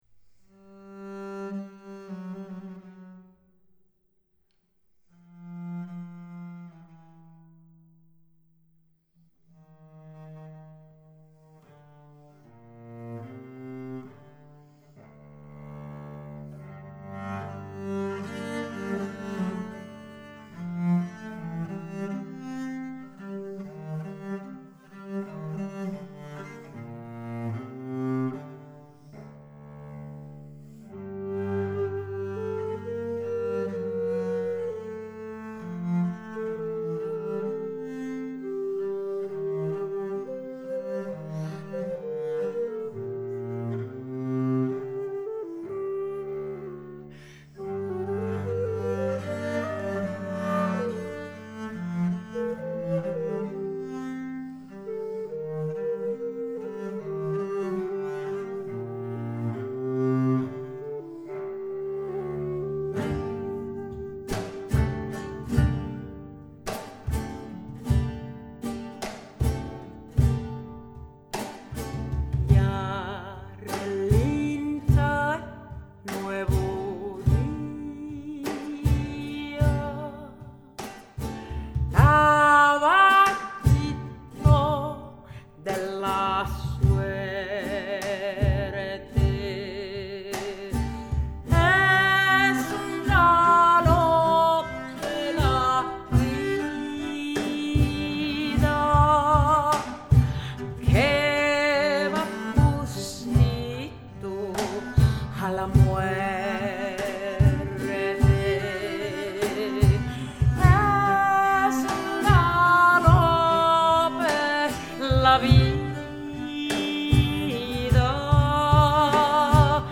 Flûtiste virtuose et chanteuse argentine